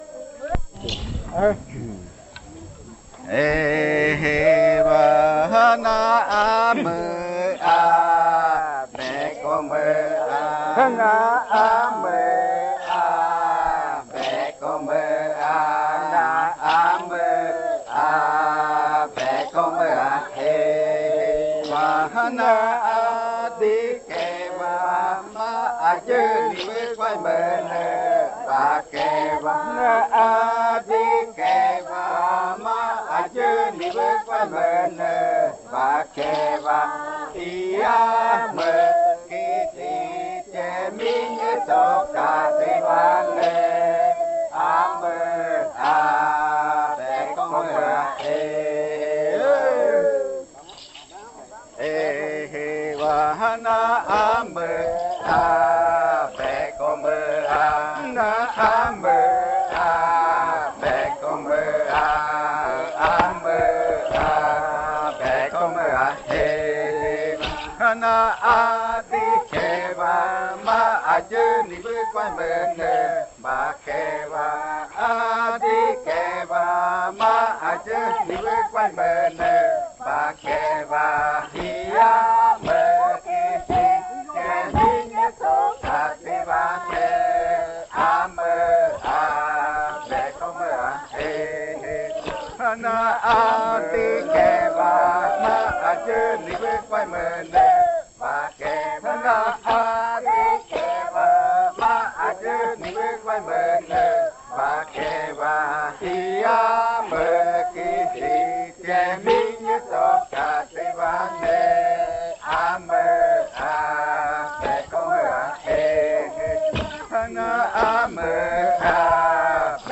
46. Baile de nombramiento. Canto n°2
Puerto Remanso del Tigre, departamento de Amazonas, Colombia
Segundo canto del baile de Pichojpa Majtsi (de nombramiento) del pueblo miraña
en casete y en el año de 1990
Se escuchan las voces principales